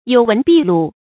有闻必录 yǒu wén bì lù
有闻必录发音